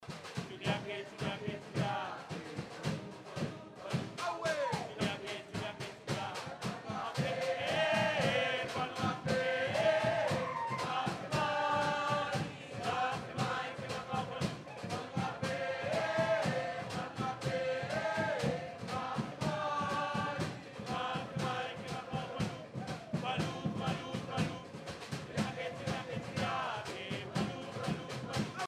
swains-song.mp3